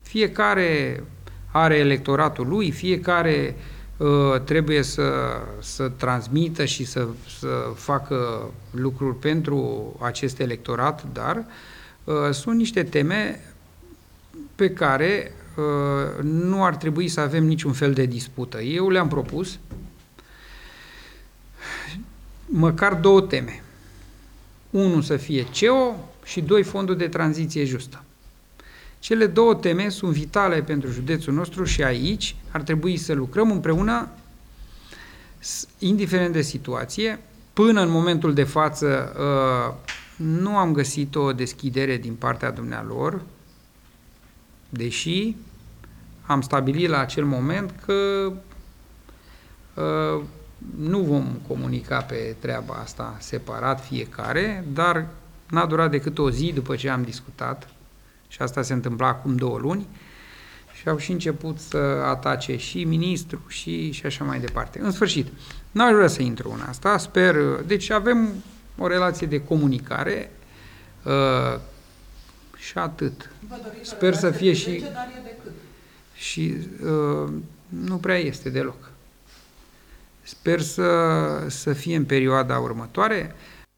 Președintele PNL Gorj, senatorul Ion Iordache, și-ar dori ca relația sa cu Partidul Social Democrat să fie una mai bună, cel puțin în ceea ce privește două teme de o importanță covârșitoare pentru județul Gorj: Complexul Energetic Oltenia și Fondul de Tranziție Justă. Într-o conferință de presă, Iordache a declarat că nu a găsit o deschidere din partea PSD, astfel că nu a ajuns la o înțelege în privința unei posibile colaborări.
Ion Iordache, Președinte PNL